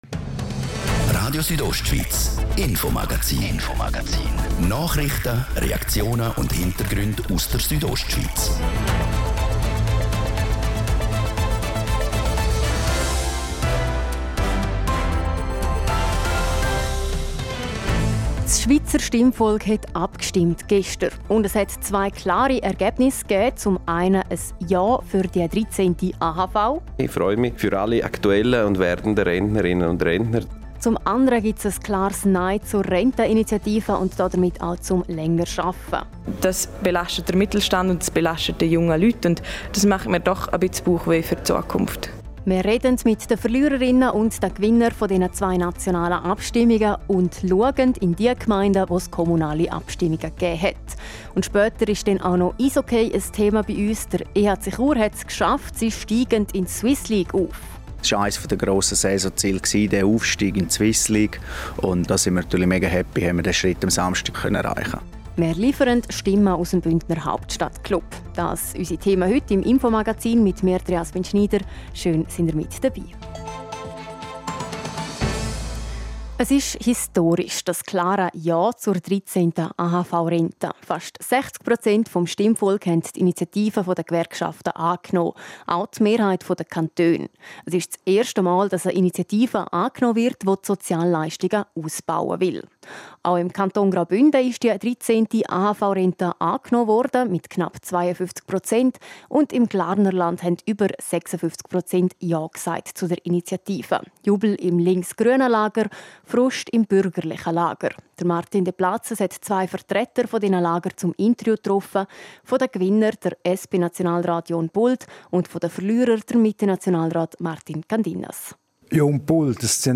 Das Infomagazin zum Wochenstart mit folgenden Themen: